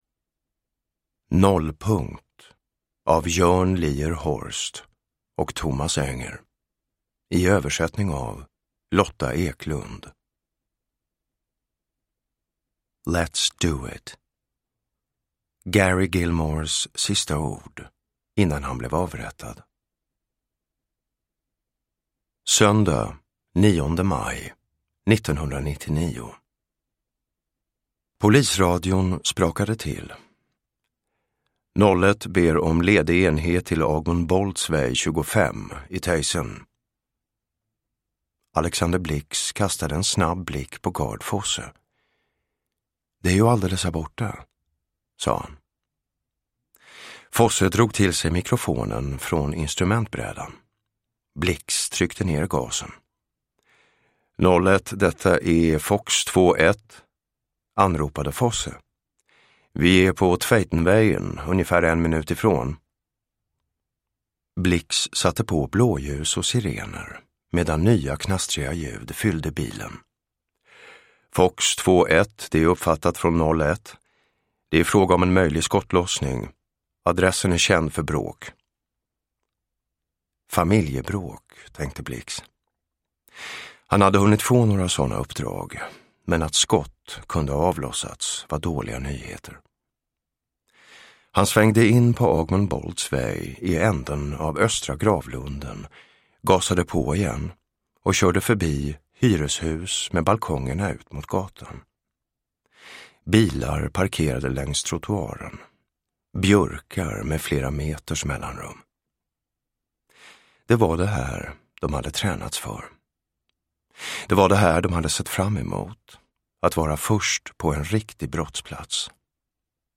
Nollpunkt – Ljudbok – Laddas ner
Uppläsare: Jonas Malmsjö